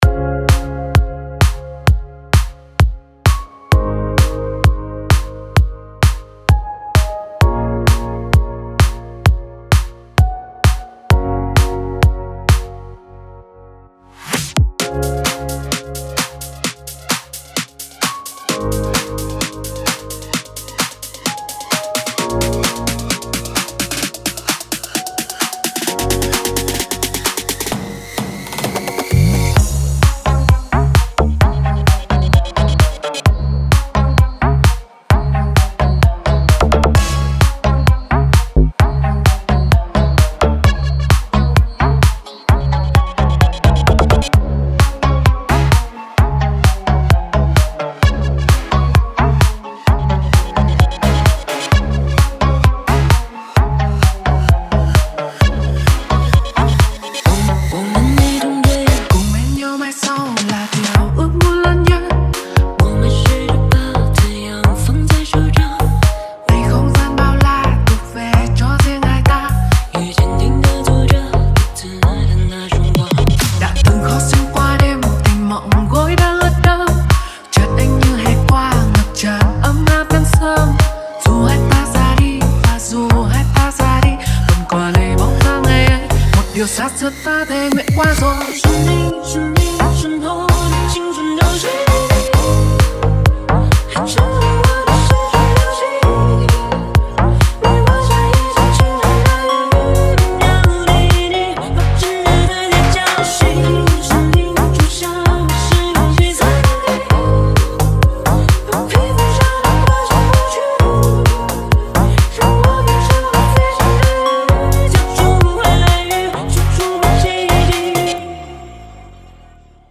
5天前 DJ音乐工程 · VinaHouse 2 推广